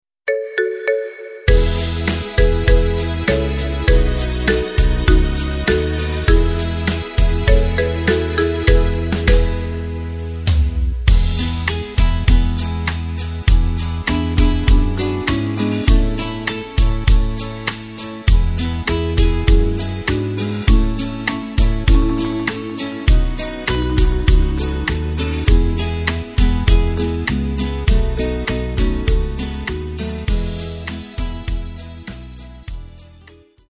Takt: 4/4 Tempo: 100.00 Tonart: E
Schlager